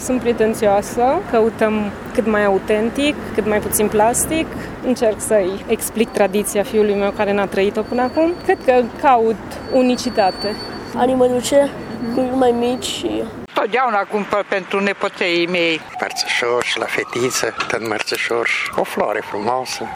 Cumpărătorii apreciază calitatea, chiar dacă se uită de două ori atunci când trebuie să dea 10 lei pe un mărțișor hand-made.